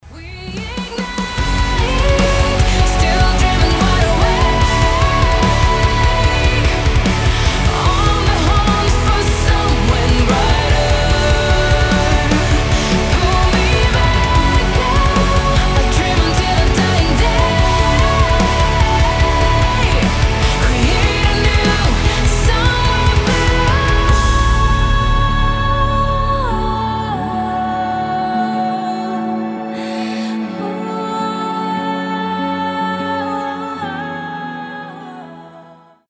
• Качество: 320, Stereo
громкие
женский голос
Alternative Metal